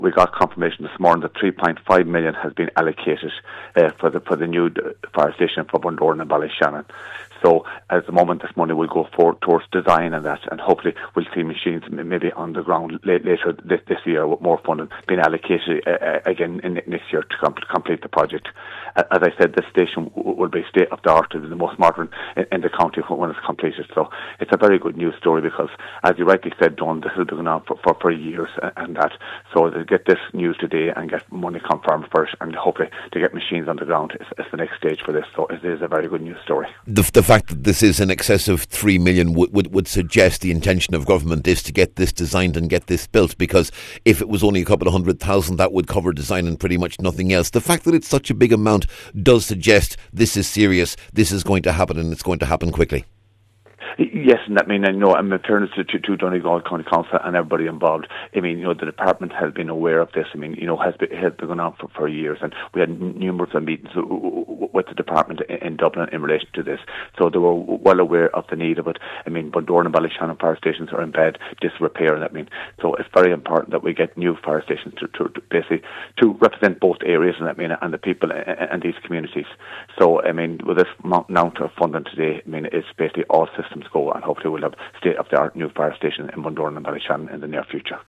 Donegal MD Cathaoirleach Michael Naughton says this is a very important allocation, as the amount will allow for planning and design, and also he hopes, for a start to be made on construction……